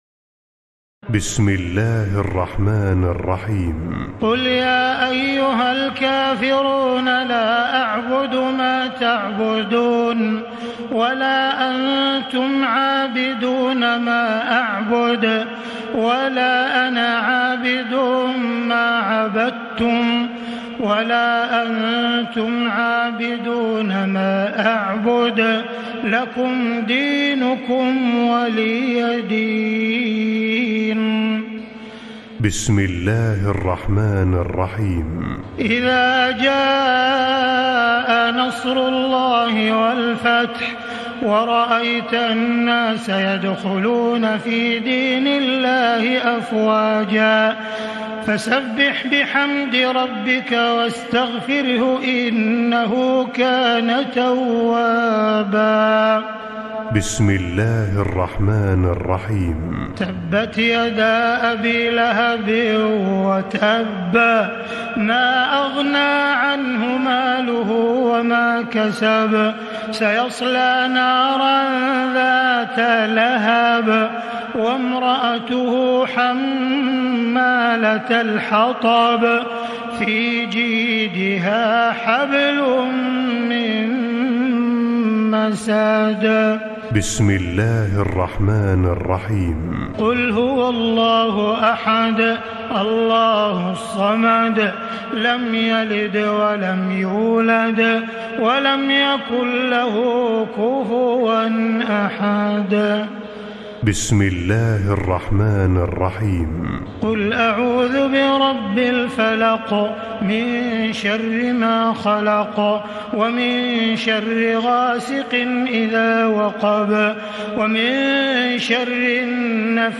تراويح ليلة 29 رمضان 1440هـ من سورة الكافرون الى الناس Taraweeh 29 st night Ramadan 1440H from Surah Al-Kaafiroon to An-Naas > تراويح الحرم المكي عام 1440 🕋 > التراويح - تلاوات الحرمين